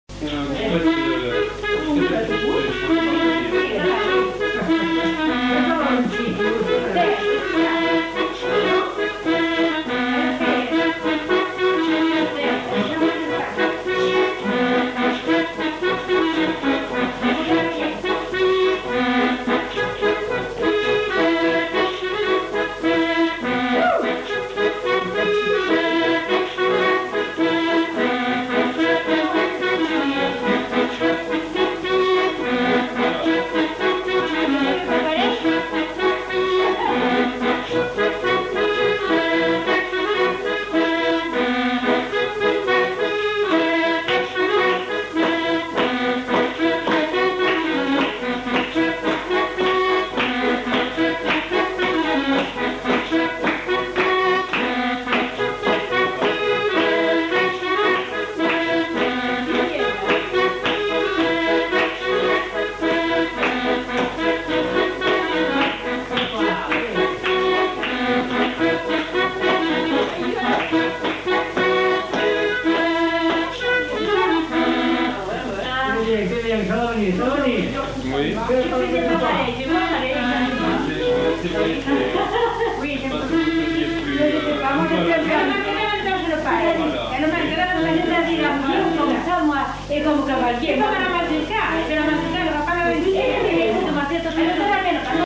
Répertoire de danses joué à l'accordéon diatonique
enquêtes sonores